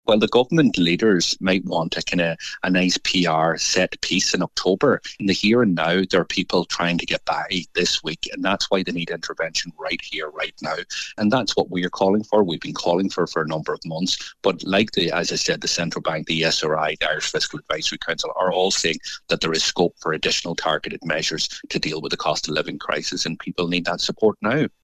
Sinn Féin’s finance spokesperson, Donegal Deputy Pearse Doherty, says four months is too long to wait for further action: